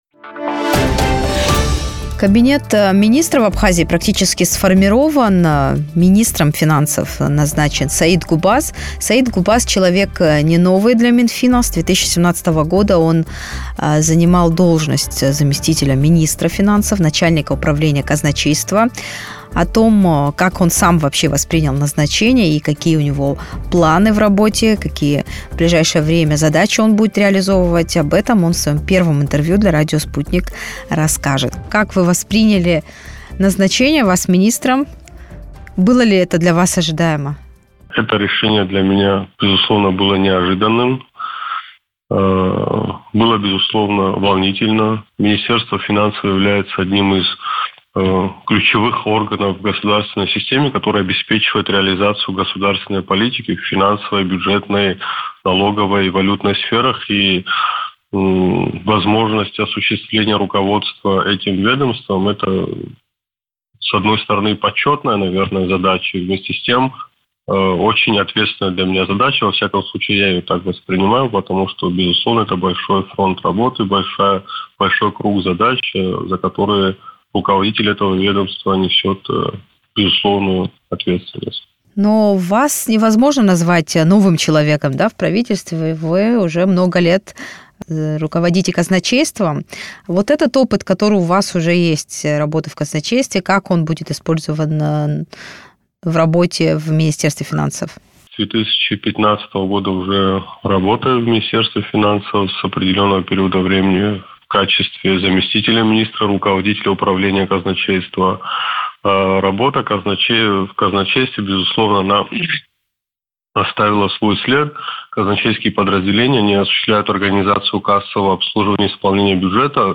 Министр финансов республики Абхазия Саид Губаз в интервью радио Sputnik рассказал, как будет строить работу на посту главы ведомства.